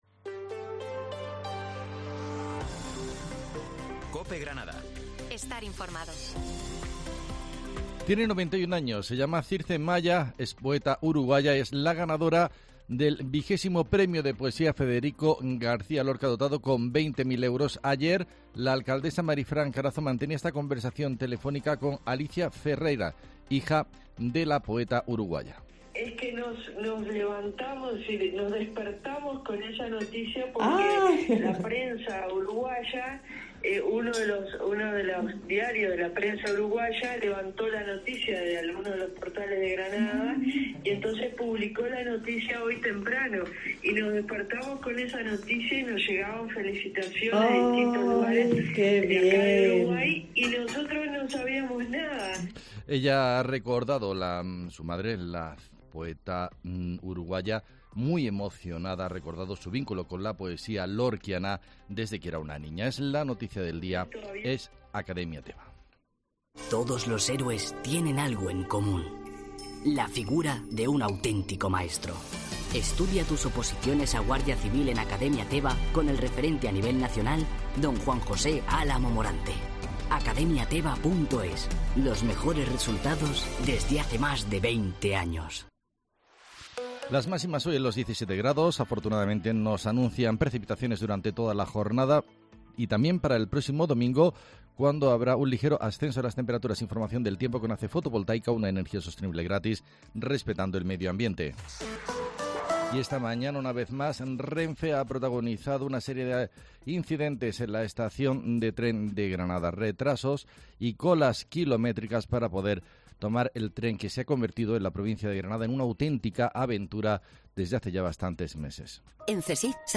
Herrera en Cope, informativo 20 de octubre